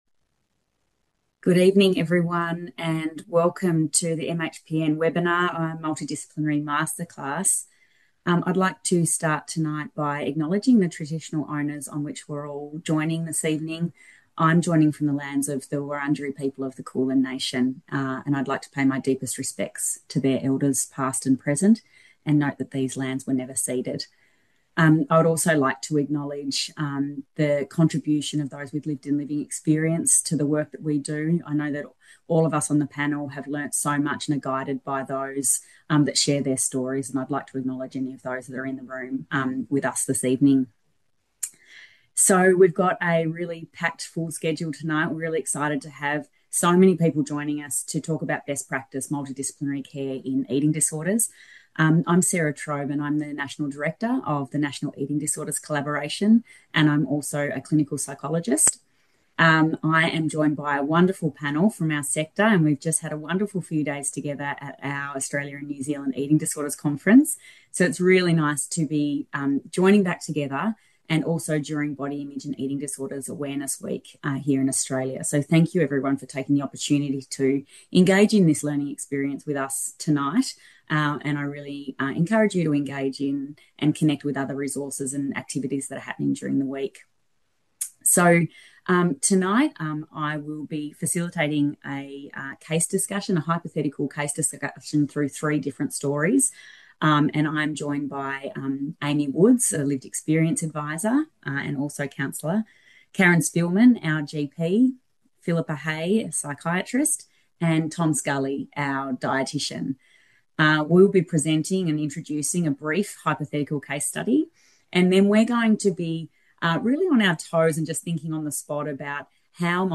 At this webinar, our panel explored how a multidisciplinary approach can lead to better outcomes in the treatment and support of eating disorder presentations. Participants will learn about the complexities of care, the benefits of cross-sector collaboration, and gain practical skills, strategies, and insights to build more effective multidisciplinary teams.